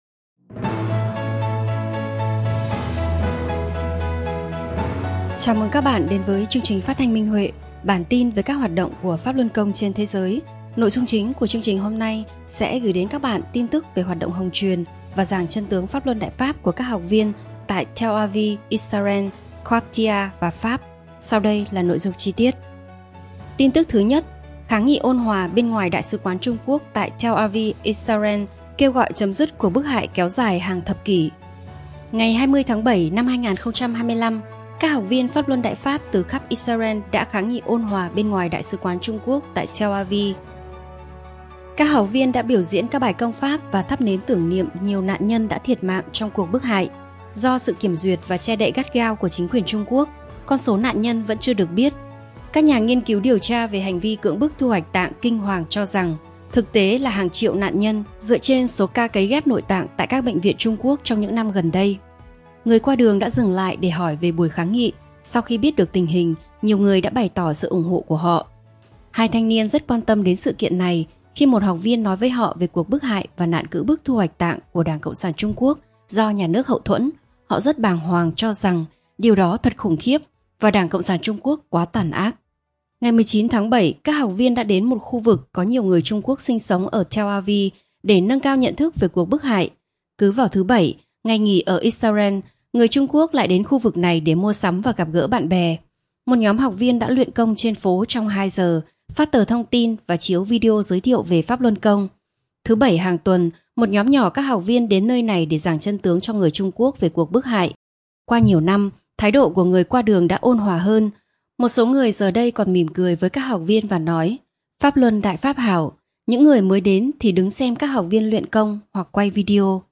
Chương trình phát thanh số 357: Tin tức Pháp Luân Đại Pháp trên thế giới – Ngày 4/8/2025